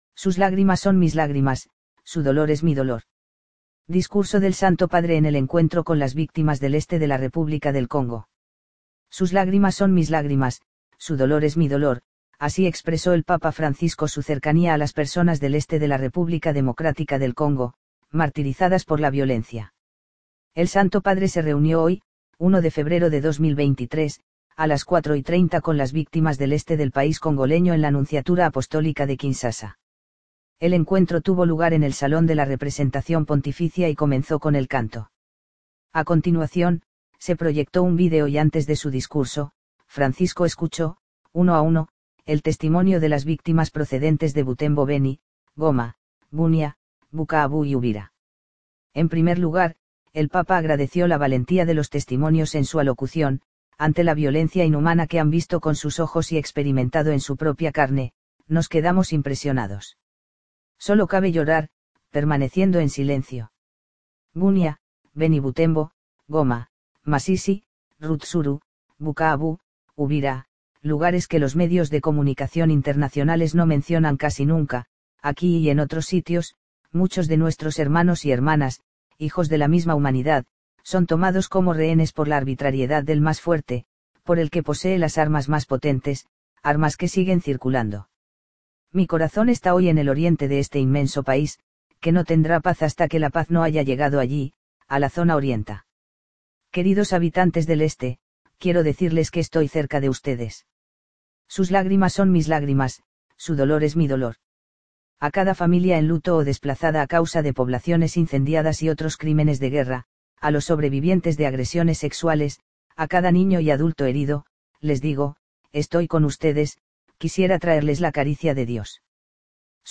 Discurso del Santo Padre
El Santo Padre se reunió hoy, 1 de febrero de 2023, a las 16:30 con las víctimas del Este del país congoleño en la Nunciatura Apostólica de Kinsasa.